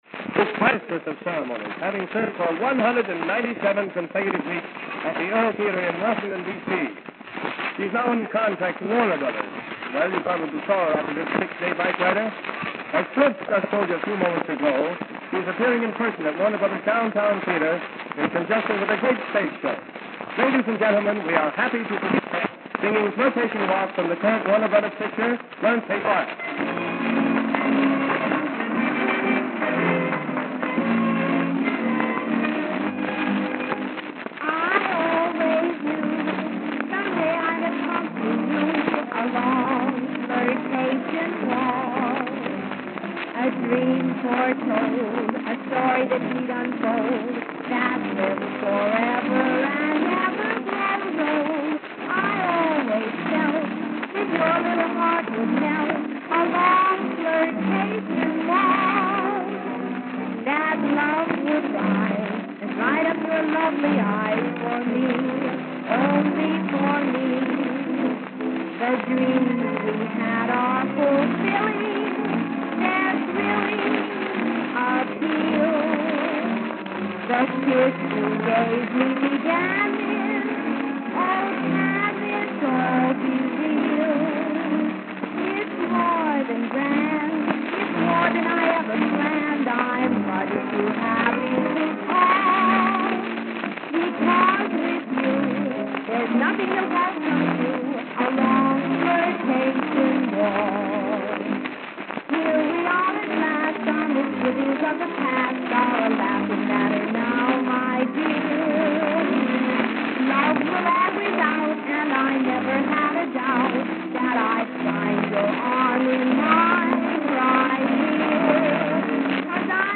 Click on Maxine Doyle to hear her singing on Radio KFWB.
Click to hear Maxine singing on KFWB radio Dec 9th 1934